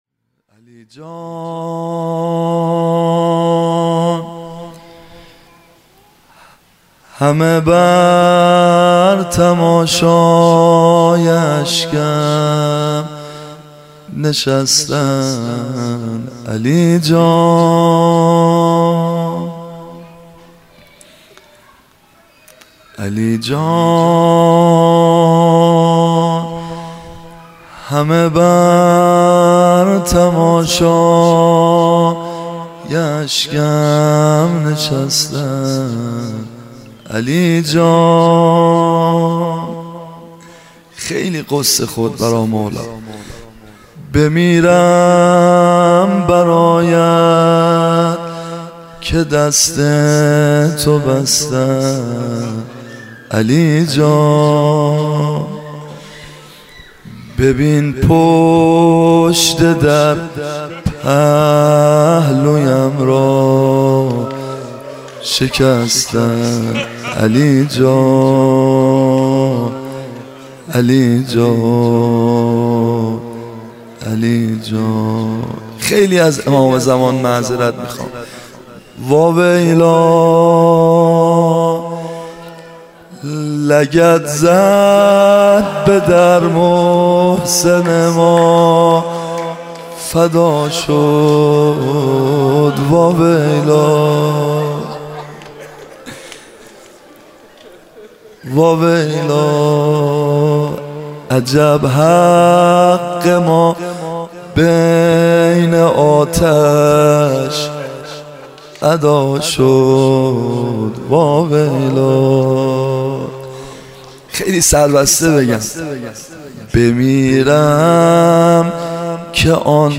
سبک زمزمه